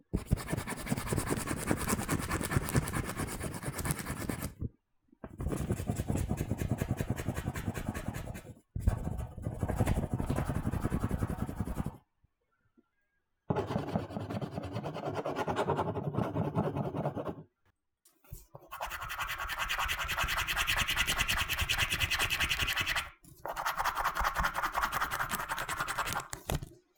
scratch.wav